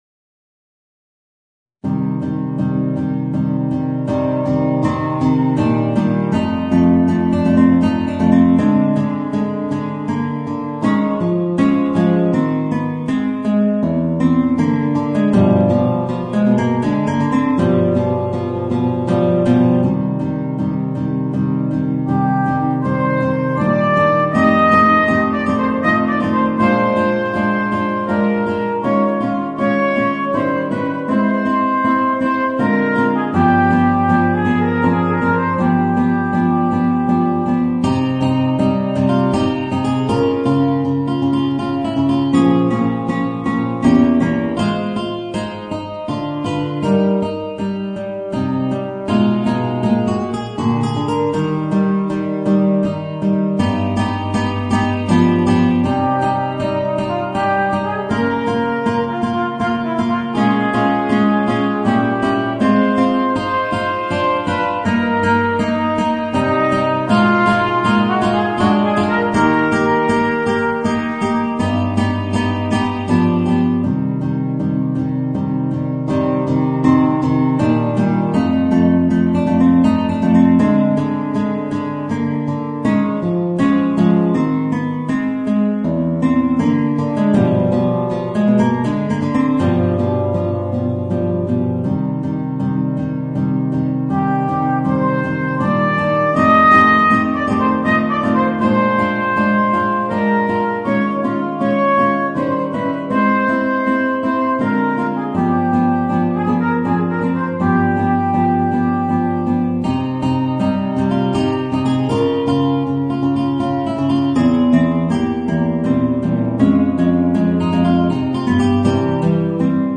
Voicing: Trumpet and Guitar